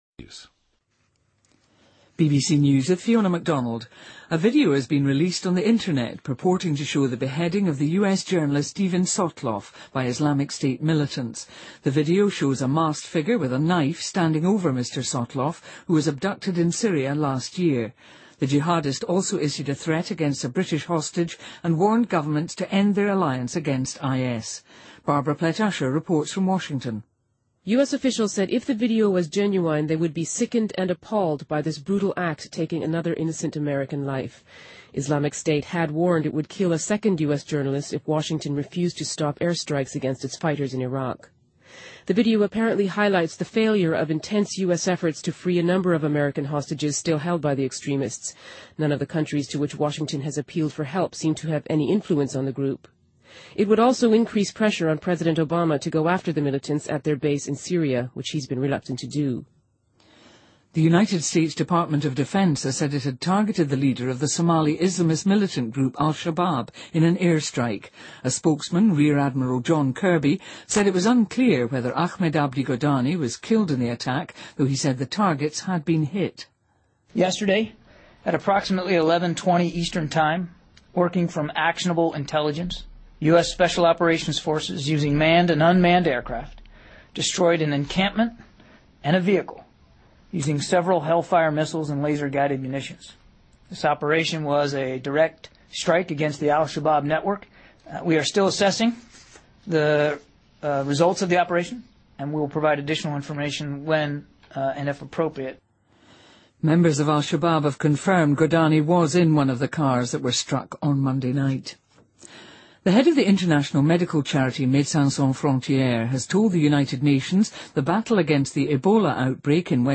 BBC news,苹果承认明星照片遭黑客有针对性地窃取